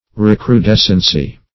Recrudescency \Re`cru*des`cen*cy\ (-d?s"sen*s?), n. [Cf. F.